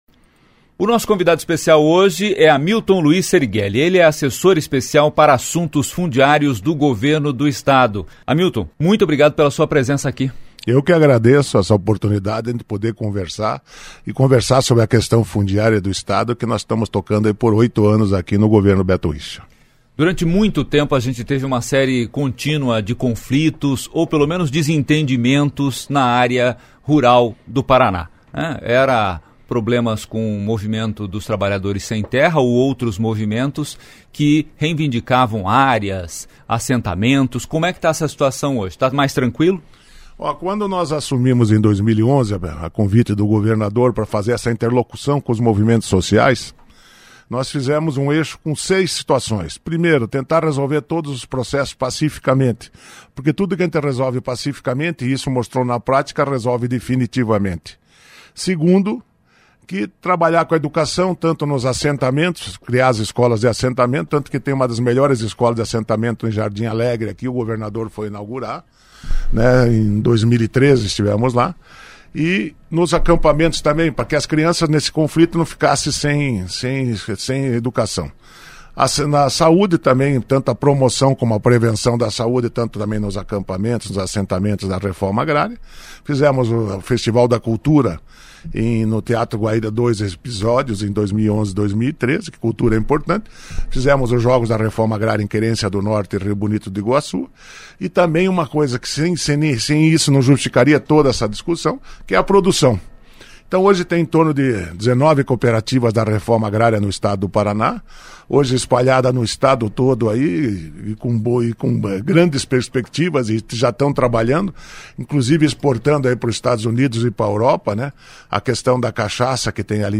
23.03 – ENTREVISTA – Bloco 1 – Hamilton Luiz Serighelli – Assessor Especial para Assuntos Fundiários do Governo do PR